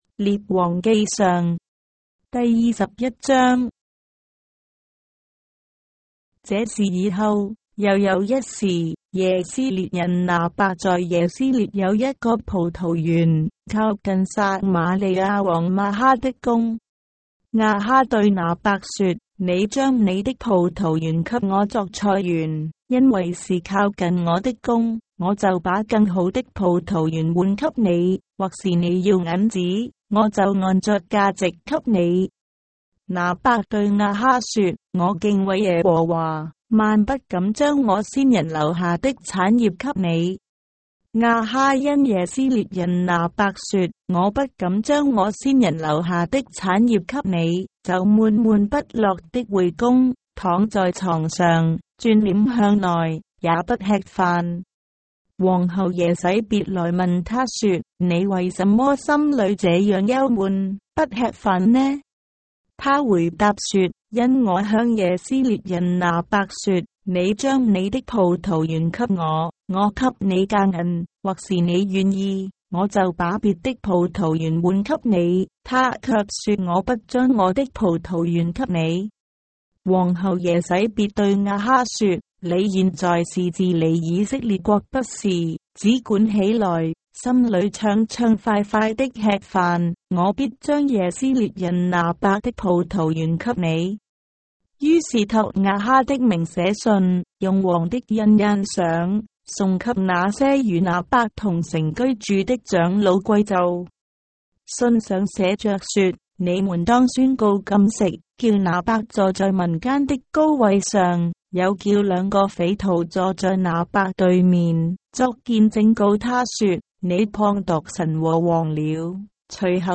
章的聖經在中國的語言，音頻旁白- 1 Kings, chapter 21 of the Holy Bible in Traditional Chinese